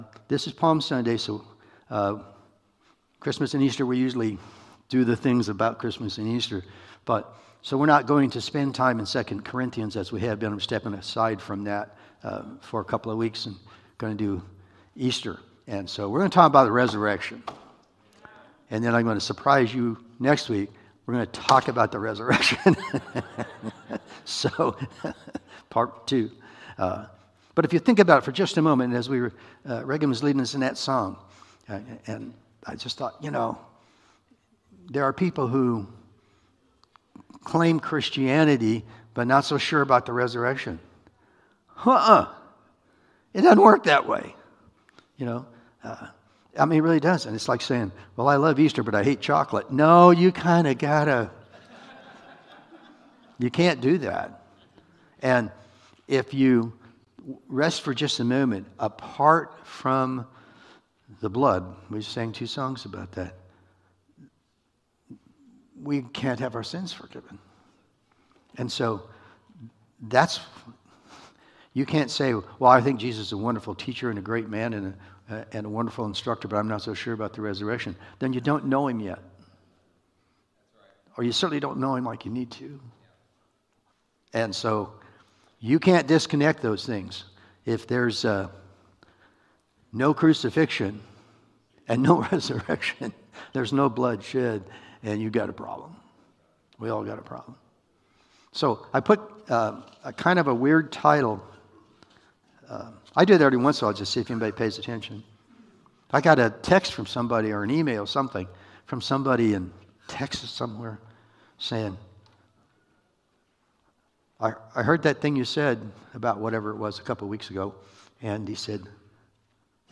1 Corinthians 15:50-58 Service Type: Holiday Sermons Download Files Notes Topics: Palm Sunday 2026